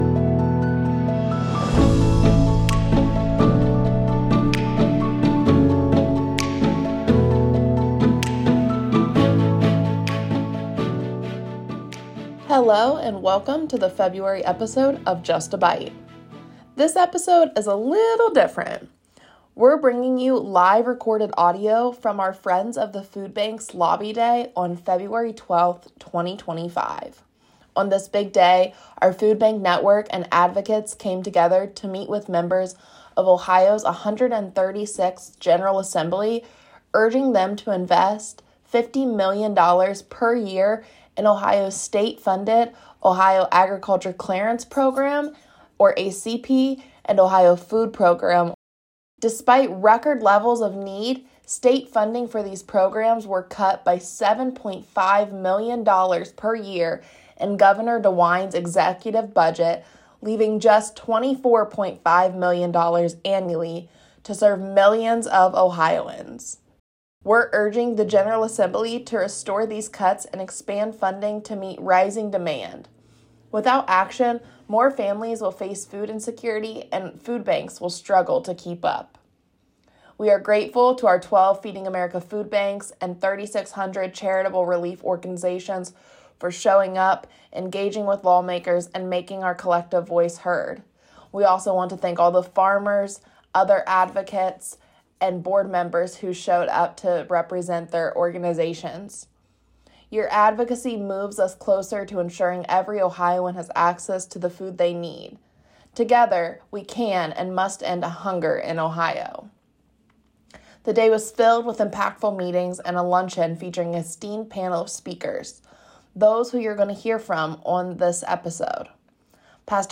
Thank you for tuning in to this special episode of Just a Bite, featuring live audio from Friends of the Foodbanks Lobby Day on February 12, 2025.